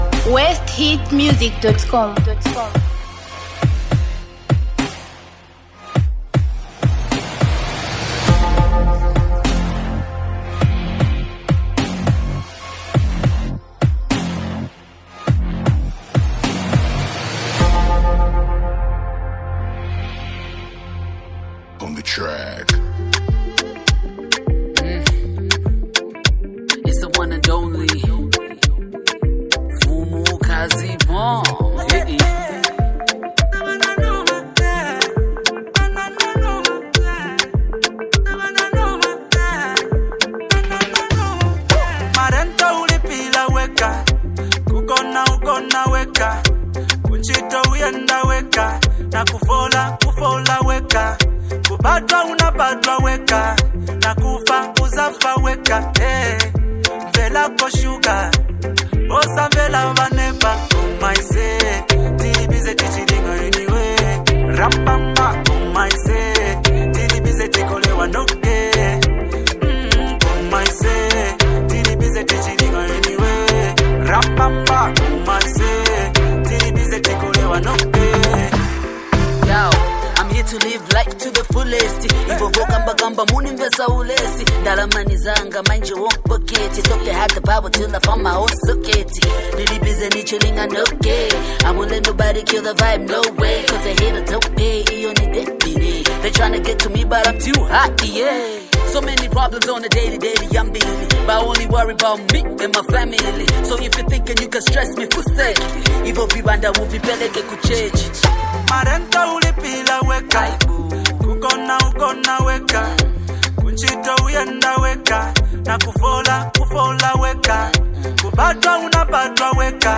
the best female rapper